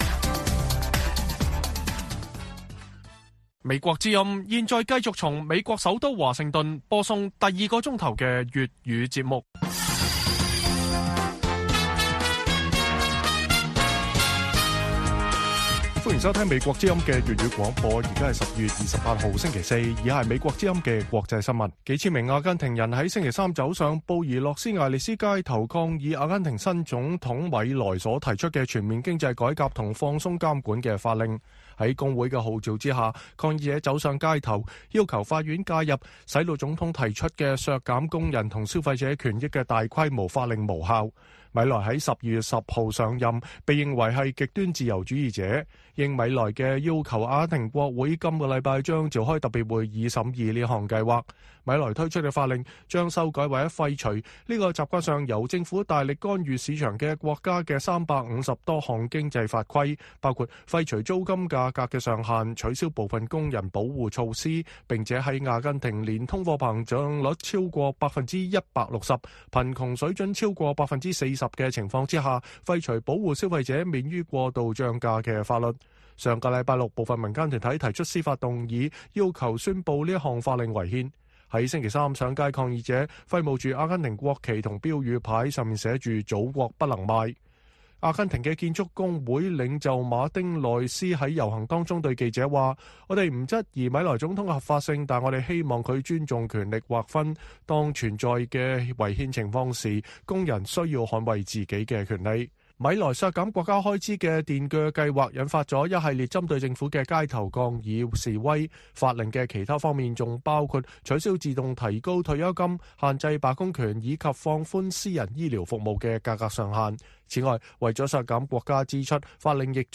粵語新聞 晚上10-11點: 北約在2023 年擴大，但烏克蘭入北約時機仍存爭議